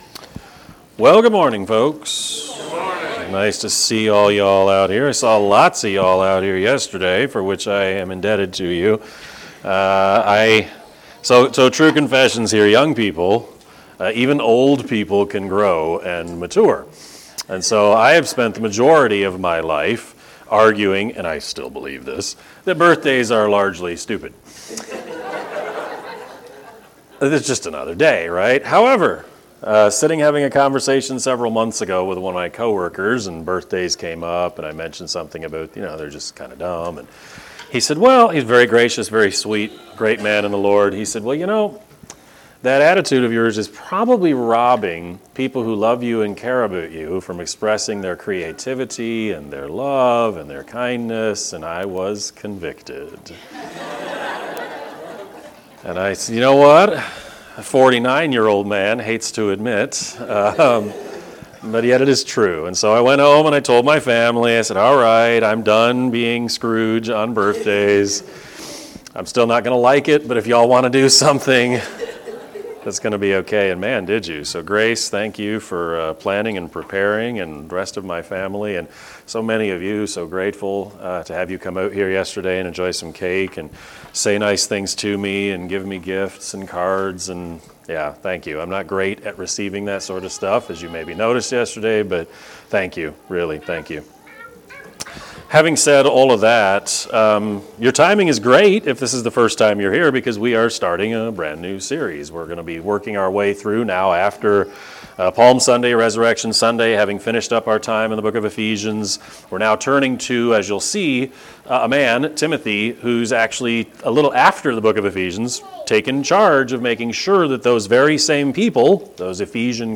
Sermon-4-16-23-Edit.mp3